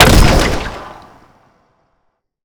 sci-fi_weapon_rifle_large_shot_01.wav